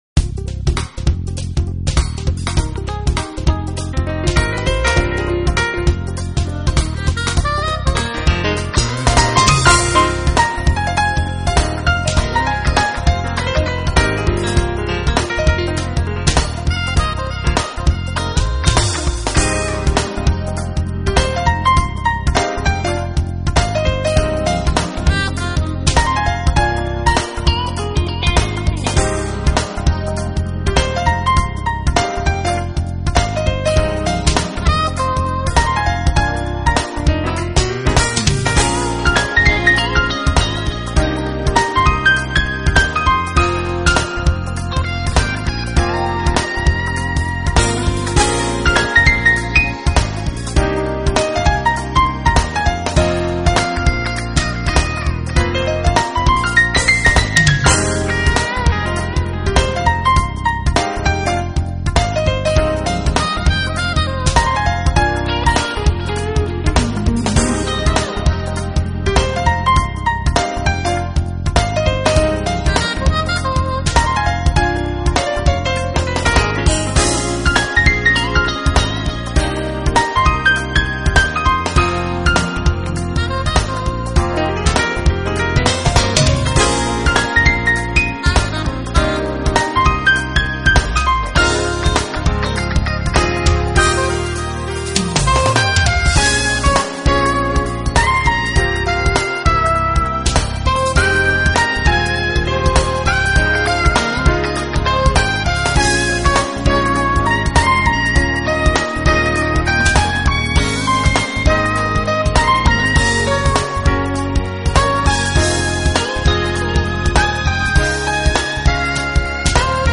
【爵士钢琴、键盘】
音樂風格: 爵士
專輯類型：Smooth Jazz
這張專輯裡的曲子主旋律多為鋼琴，時常也有薩克斯和吉他唱主角，與Bob James 那